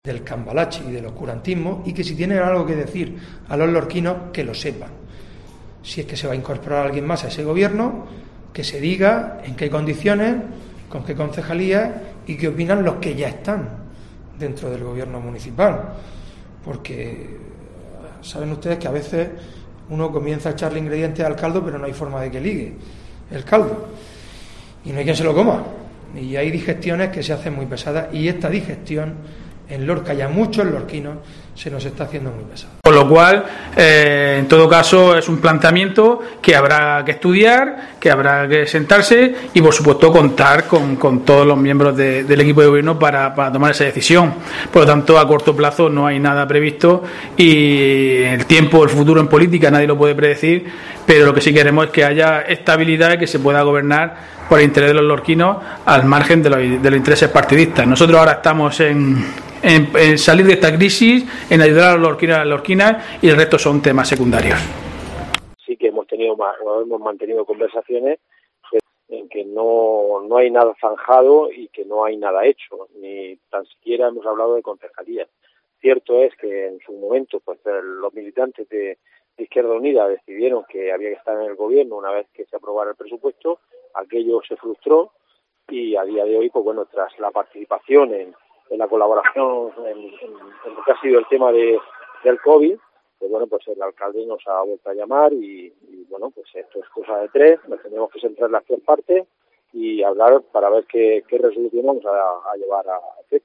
Fulgencio Gil, portavoz del PP, Diego José Mateos, alcalde de Lorca, Pedro Sosa, edil de IU Verdes